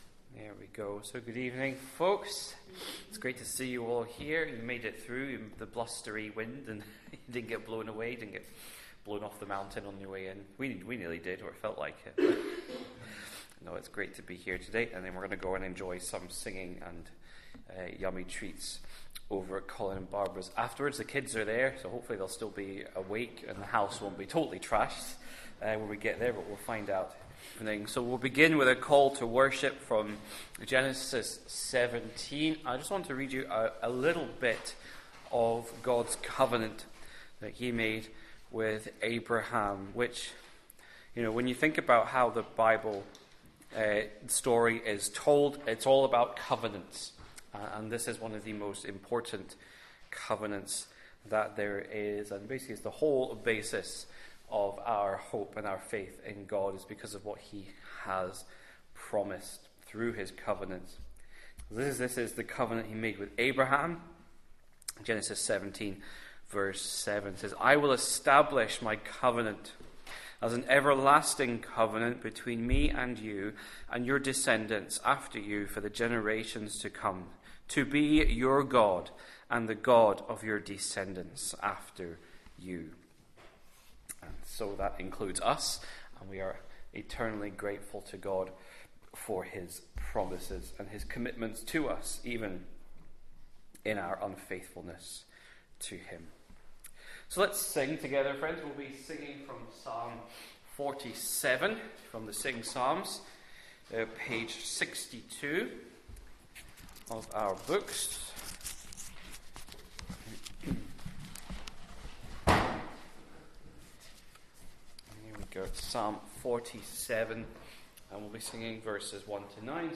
Sunday-6pm-Service-.mp3